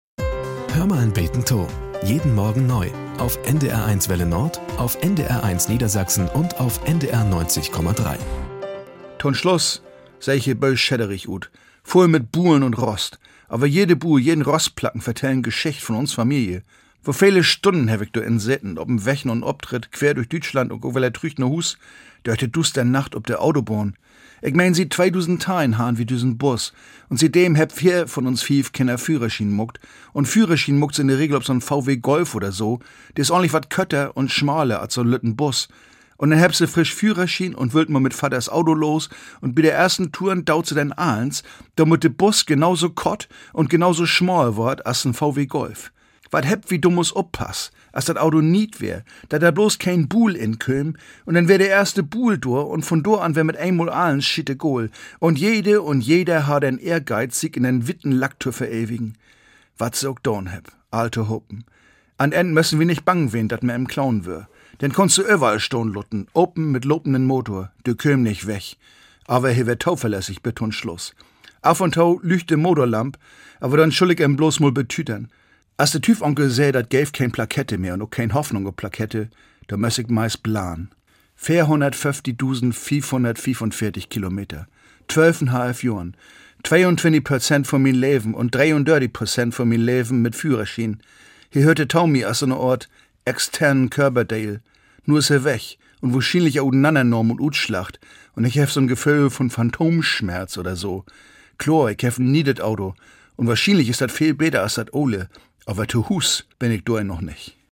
Nachrichten - 17.08.2023